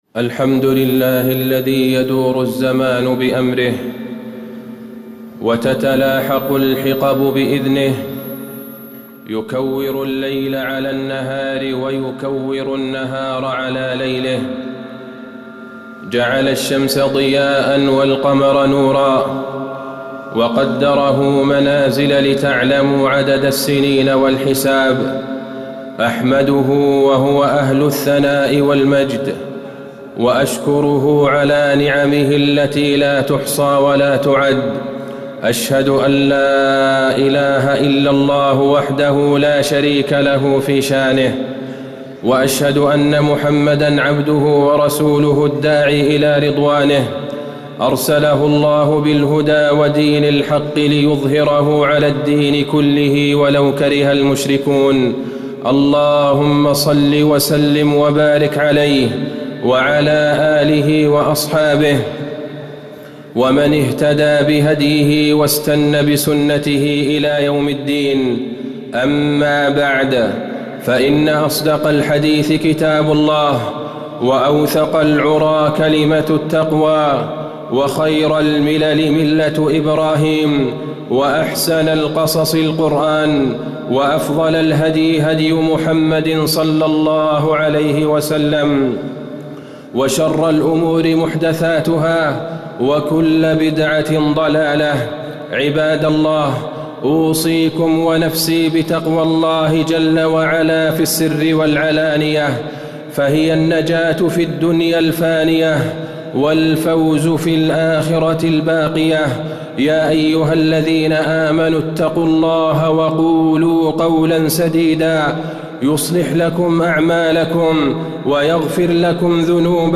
تاريخ النشر ٢٠ ربيع الأول ١٤٣٩ هـ المكان: المسجد النبوي الشيخ: فضيلة الشيخ د. عبدالله بن عبدالرحمن البعيجان فضيلة الشيخ د. عبدالله بن عبدالرحمن البعيجان الاعتبار بالصيف والشتاء